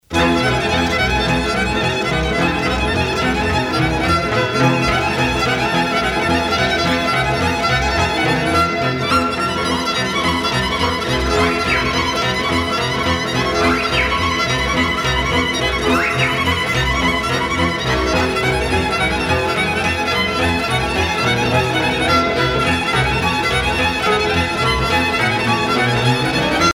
danse : sîrba (Roumanie)
Pièce musicale éditée